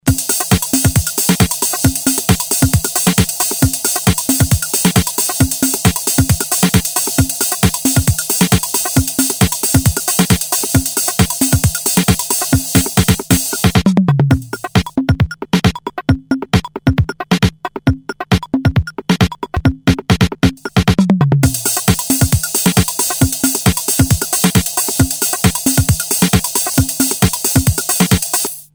Italian preset drum machine based on really lo-fi 8-bits samples with rhythm patterns.
Sounds are sampled from real drums and are pretty short on memory, only volume can be changed.
Disco samba pattern